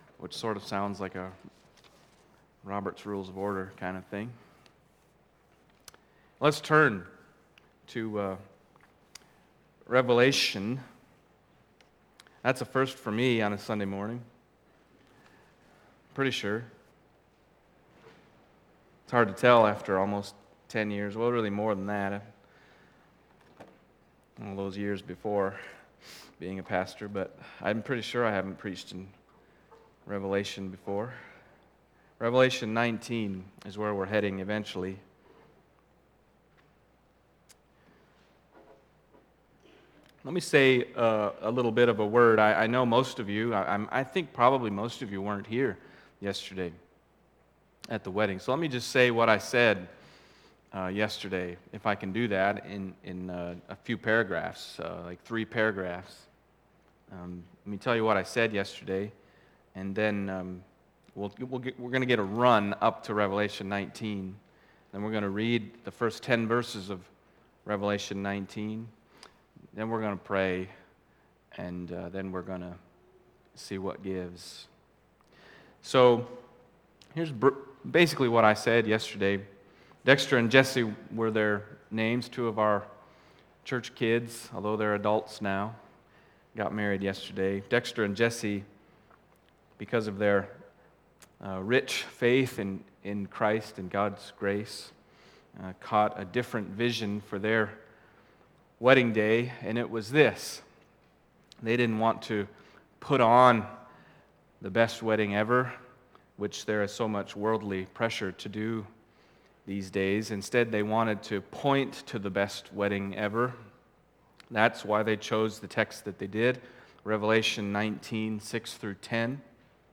Passage: Revelation 19:6-10 Service Type: Sunday Morning